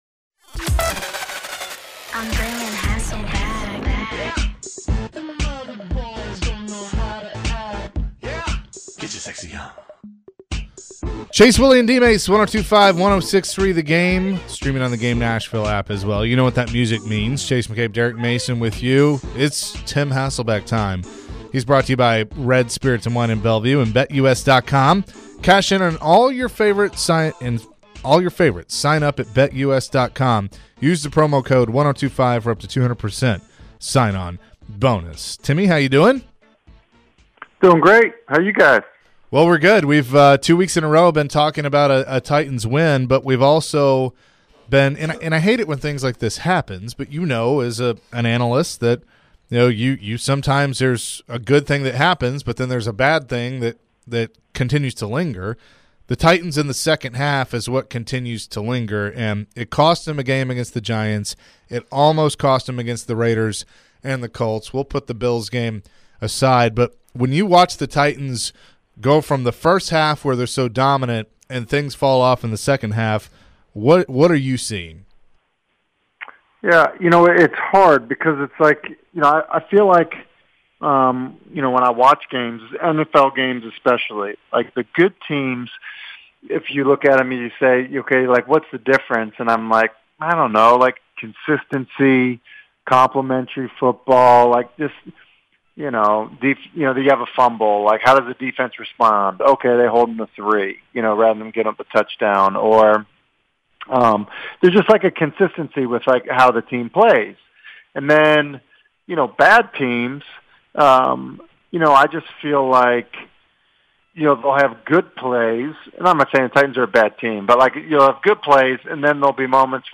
Tim Hasselbeck Full Interview (10-04-22)